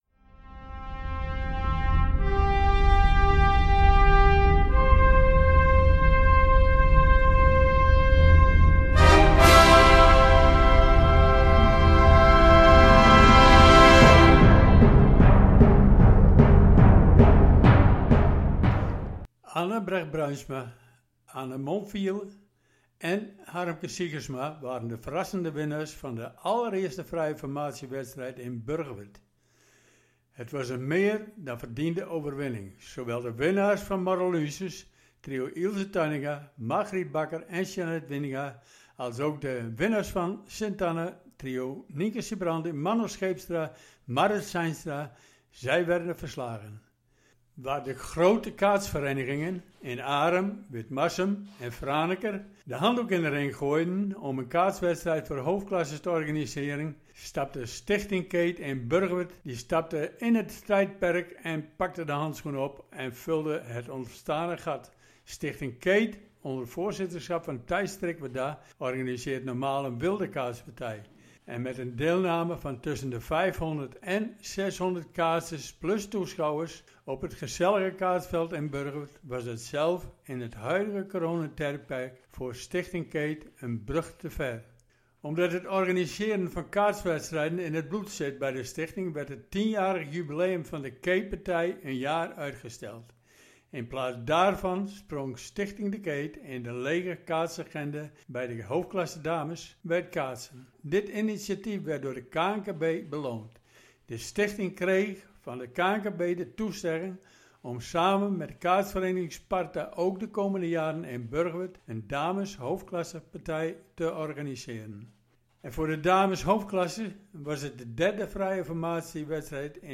Wedstrijdverslag.